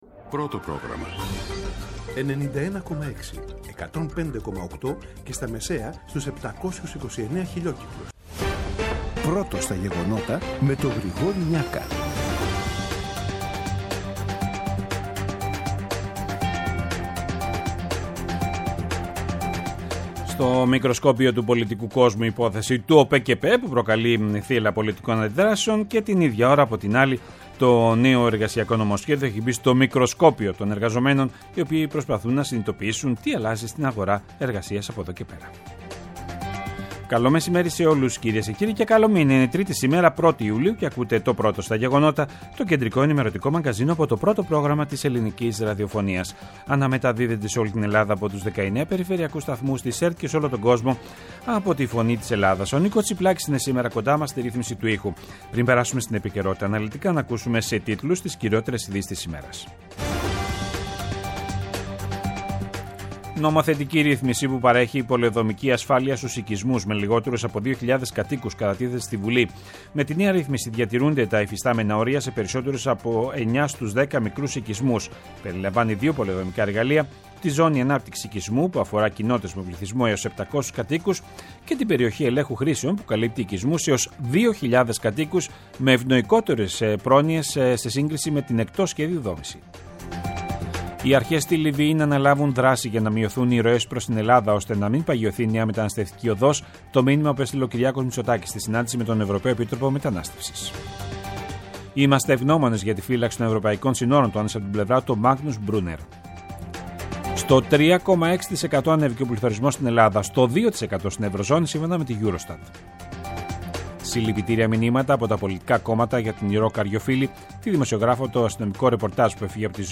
Το αναλυτικό ενημερωτικό μαγκαζίνο του Α΄ Προγράμματος στις 14:00. Με το μεγαλύτερο δίκτυο ανταποκριτών σε όλη τη χώρα, αναλυτικά ρεπορτάζ και συνεντεύξεις επικαιρότητας.